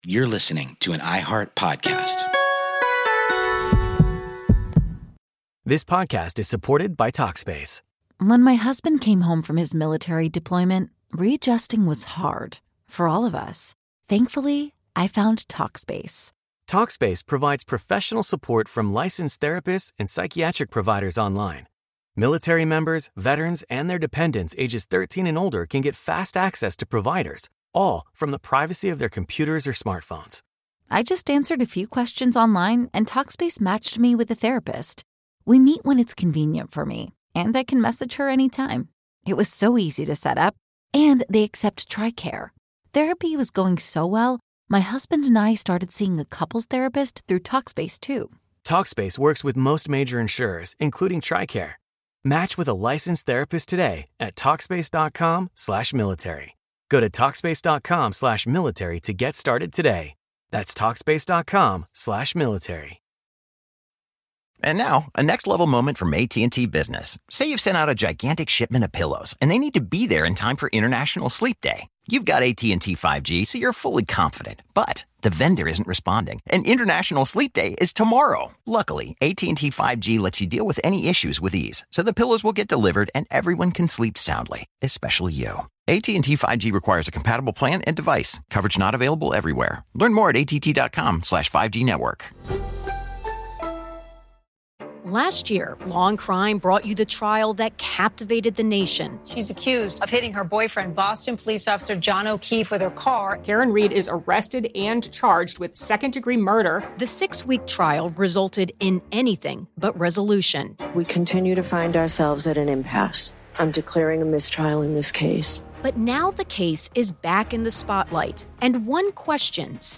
A special goes out to the Bill of Rights Institute for allowing us access to this audio, originally part of their Scholar Talks series on YouTube.